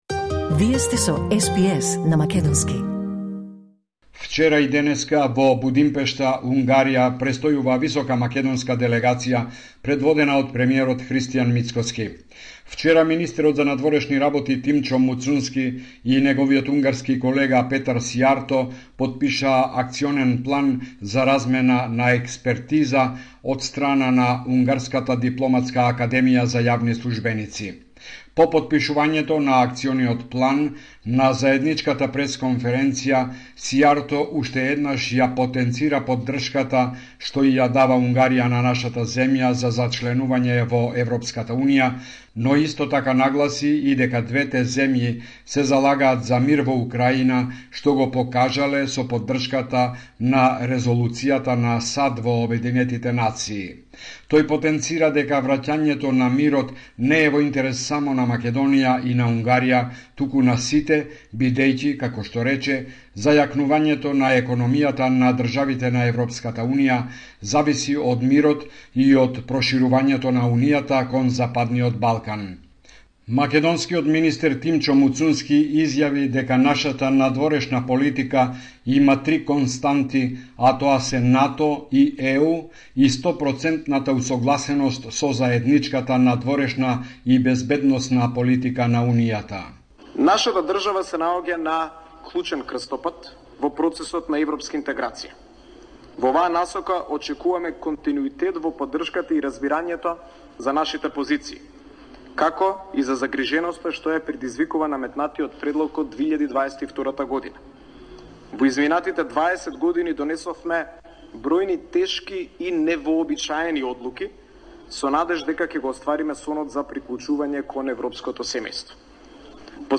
Извештај од Македонија 4 март 2025